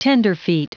Prononciation du mot tenderfeet en anglais (fichier audio)
Prononciation du mot : tenderfeet